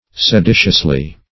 [1913 Webster] -- Se*di"tious*ly, adv.